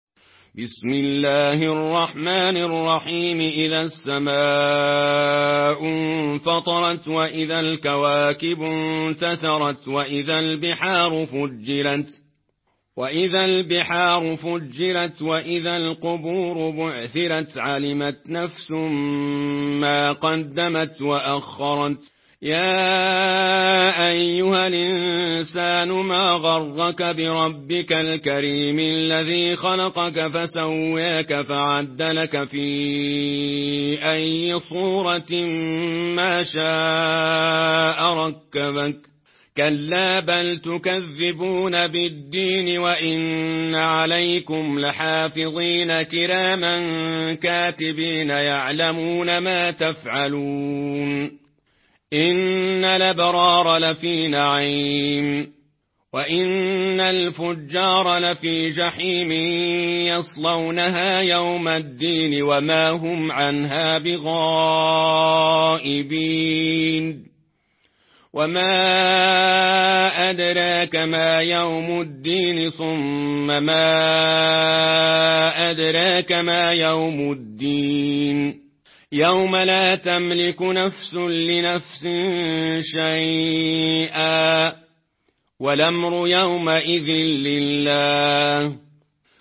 Une récitation touchante et belle des versets coraniques par la narration Warsh An Nafi.
Murattal Warsh An Nafi